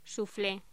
Locución: Suflé